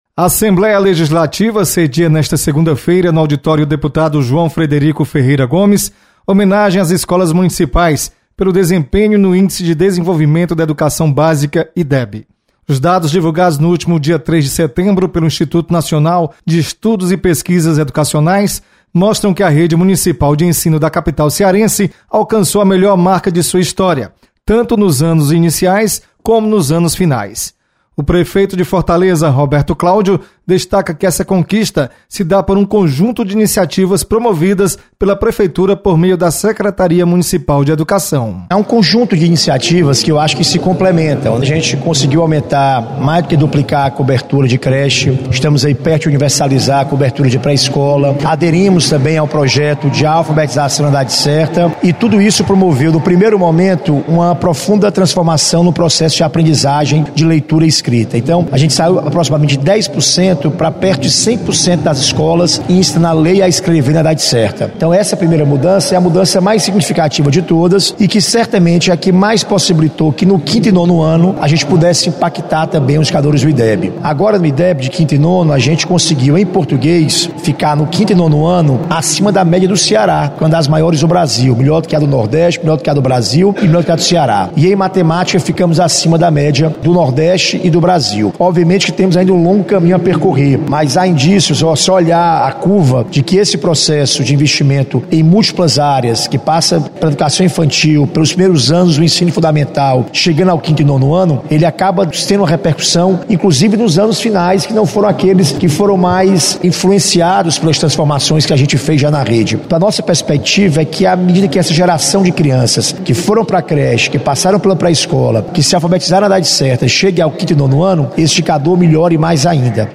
Você está aqui: Início Comunicação Rádio FM Assembleia Notícias Homenagem